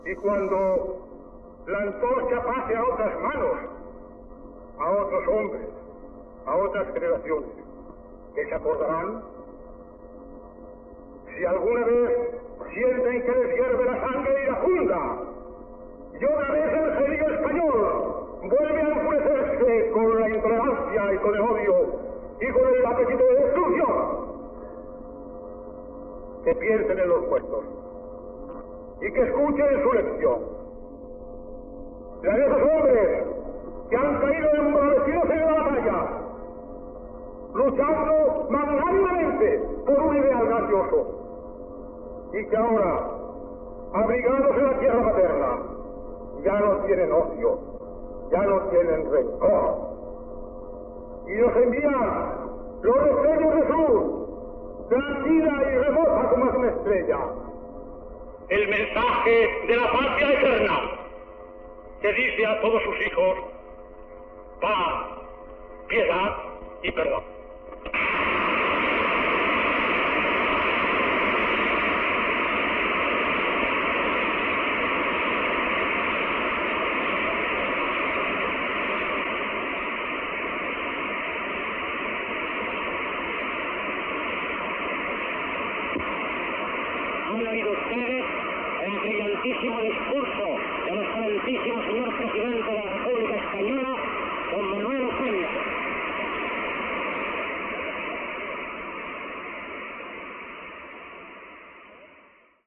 Últim discurs del president de la II República espanyola Manuel Azaña, pronunciat a l'Ajuntament de Barcelona.
Part final del discurs, apluadiomets i paraules del locutor.